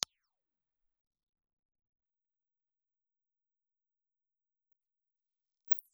Piezoelectric Crystal
Cardioid
Speech (male) recorded with a Rothermel D-104 crystal microphone.
The sound quality is impressive for a crystal microphone.